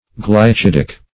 Search Result for " glycidic" : The Collaborative International Dictionary of English v.0.48: Glycidic \Gly*cid"ic\, a. (Chem.)